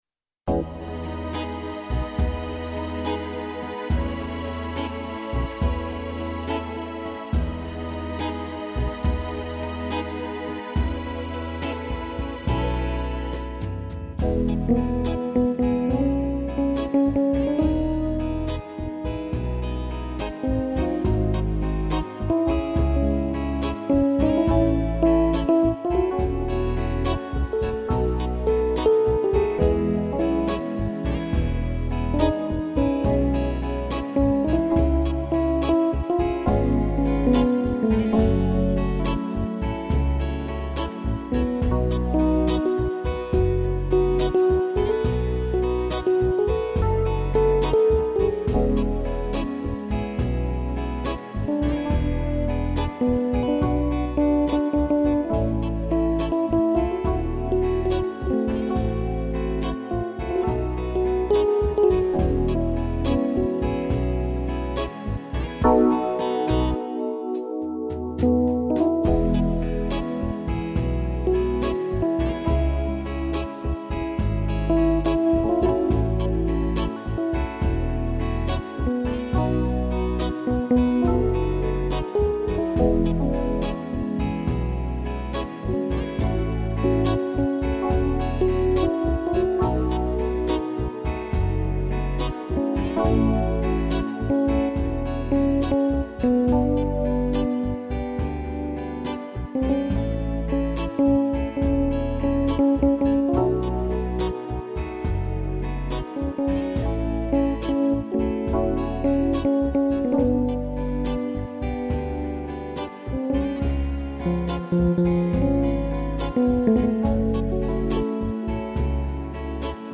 Soneto 020 (letra: Shakespeare - música e arranjo: Rocha) (instrumental)